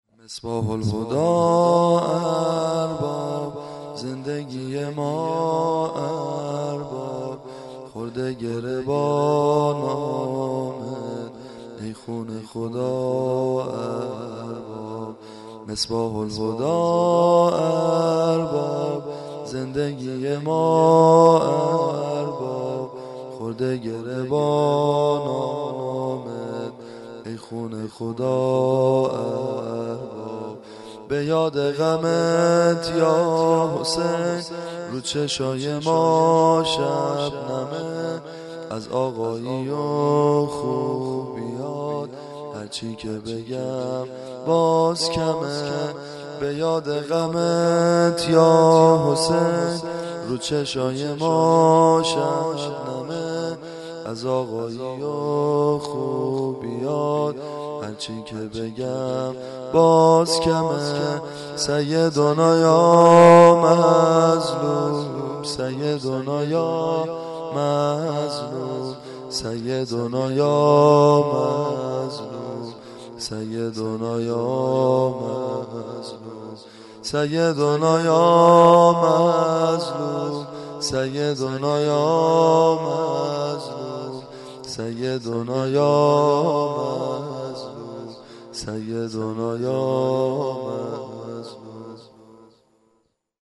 شور- دم پایانی